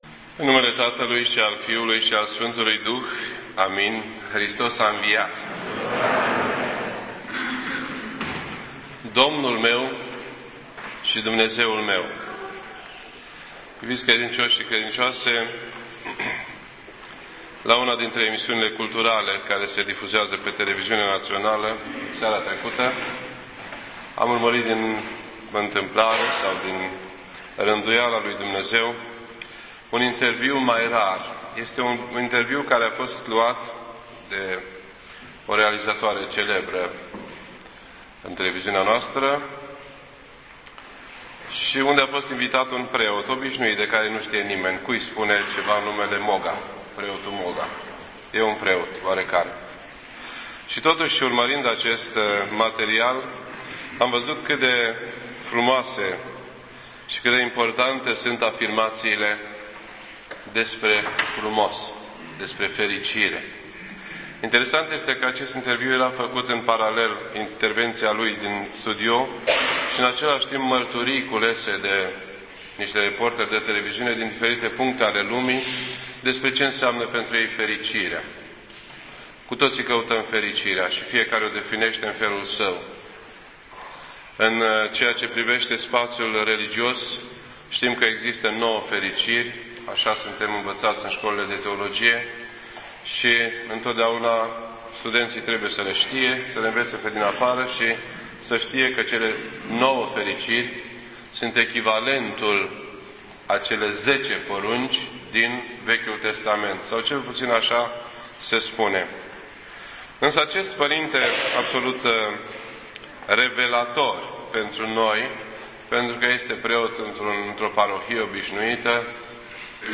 This entry was posted on Sunday, May 1st, 2011 at 7:04 PM and is filed under Predici ortodoxe in format audio.